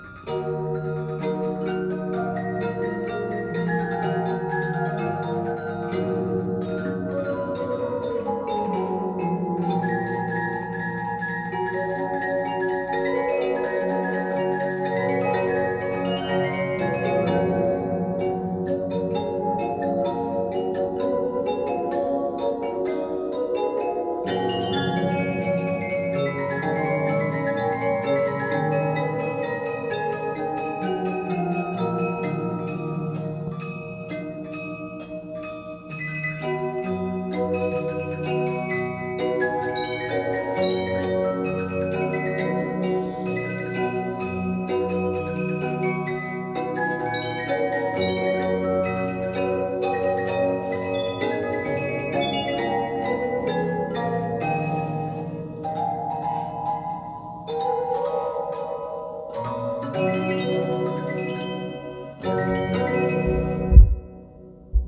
It is a very desirable double comb machine. (The combs were tuned slightly off from each other to yield a deep, rich, ethereal sound.)
The Reginaphone was a combination phonograph/music box.
Reginaphone-Style-113.mp3